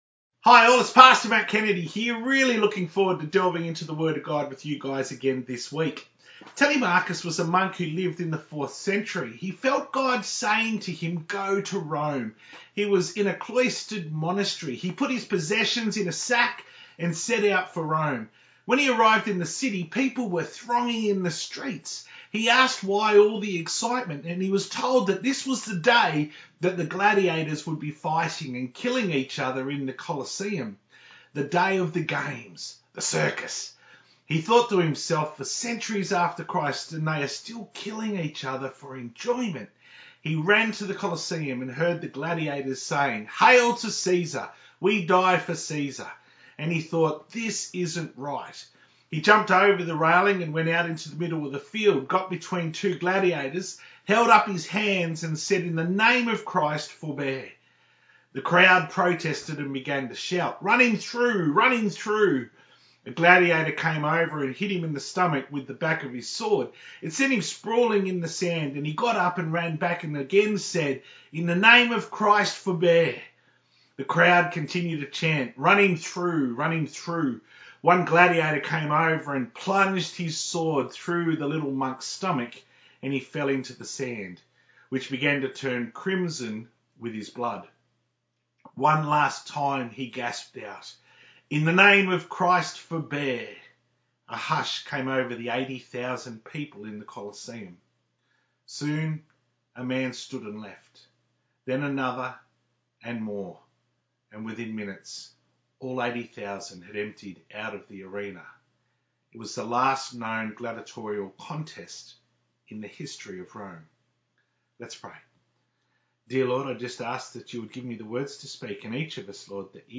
To view the Full Service from 21st March 2021 on YouTube, click here.